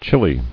[chil·li]